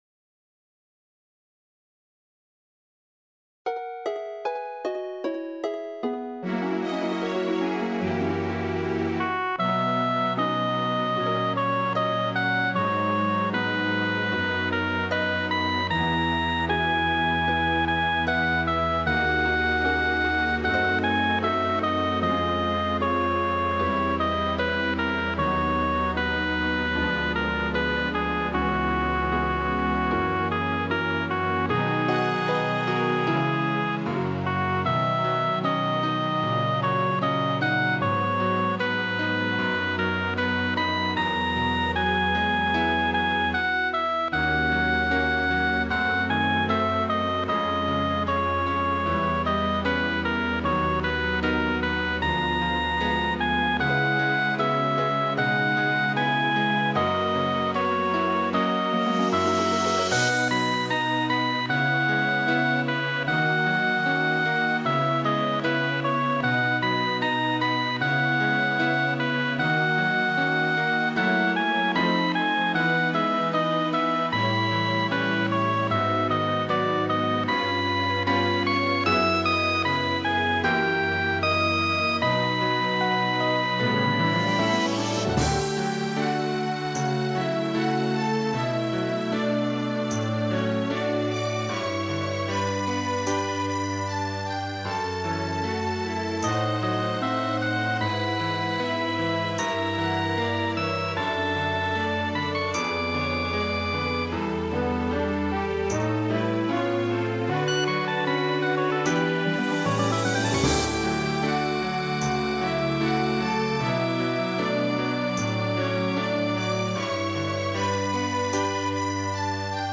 奏者の宮本文昭氏にかなうはずもないので無理な抑揚は付けていない。